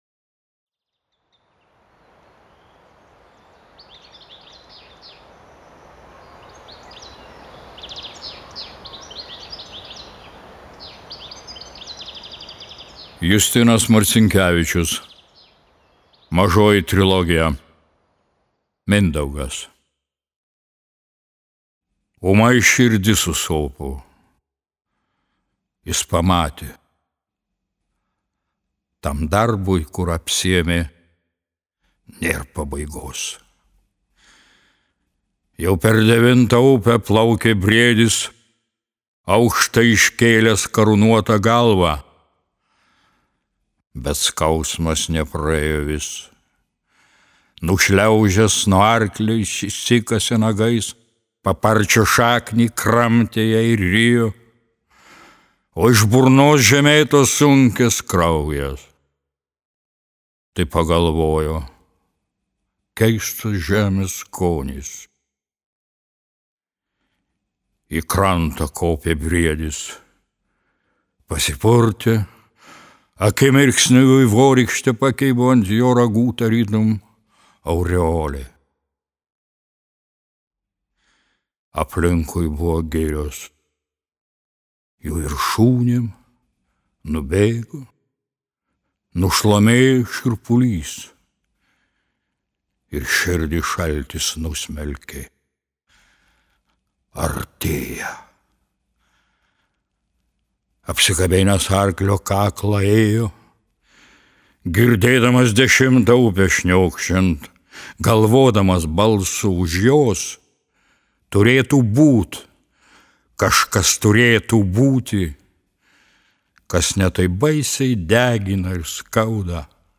Mažoji trilogija MINDAUGAS | MAŽVYDAS | KATEDRA | Skaito aktorius Vytautas Paukštė